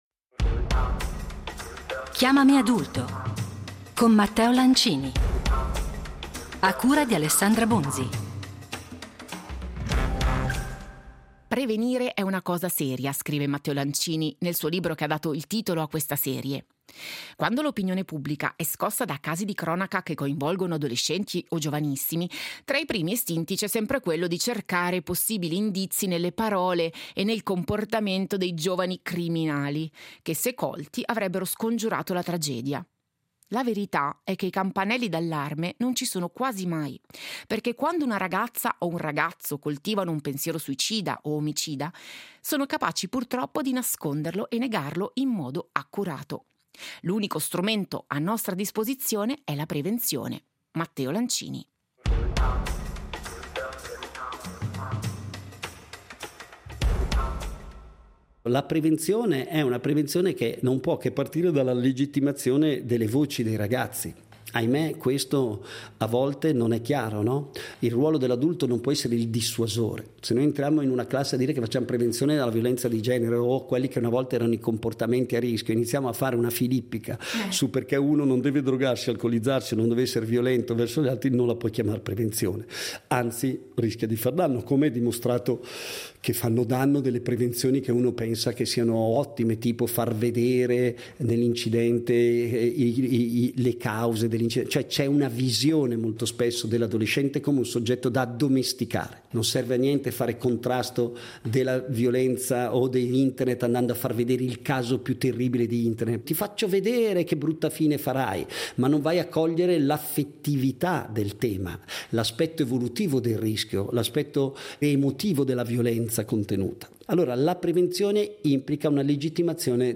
Gruppo di discussione e supporto